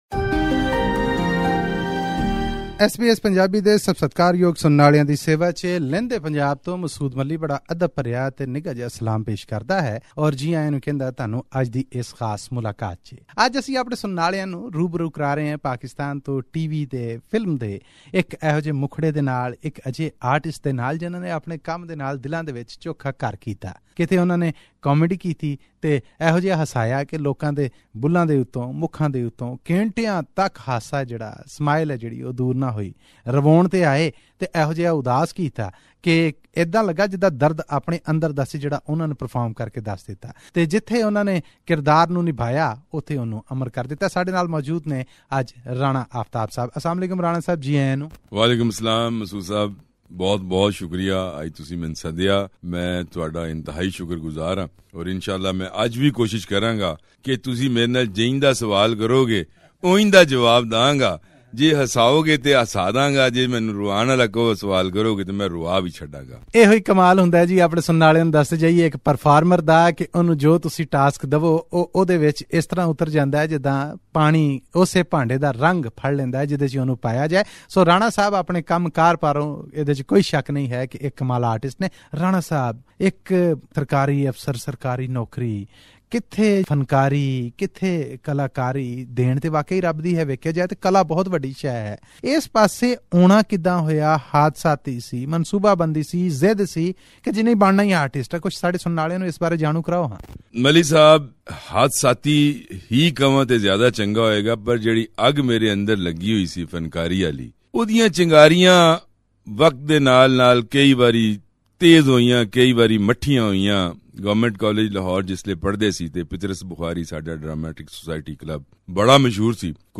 Click on the audio button to listen to this interview in Punjabi.